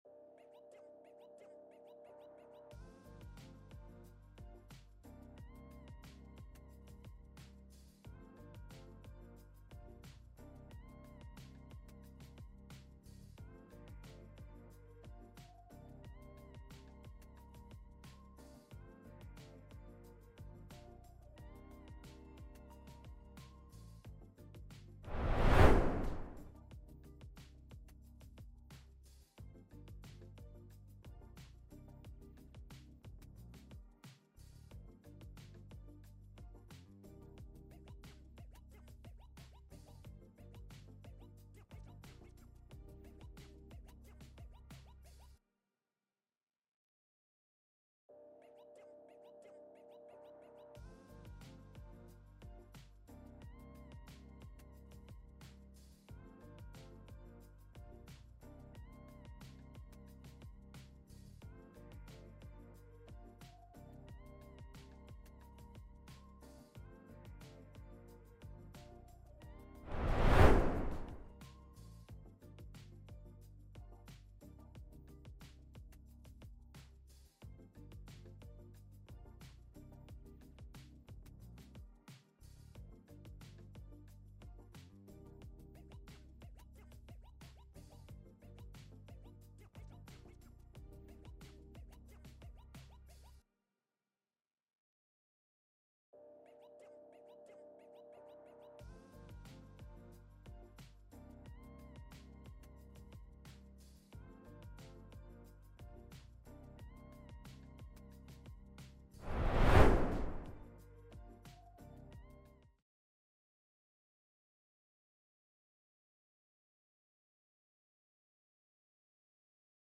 A Warts & All Chat on B2B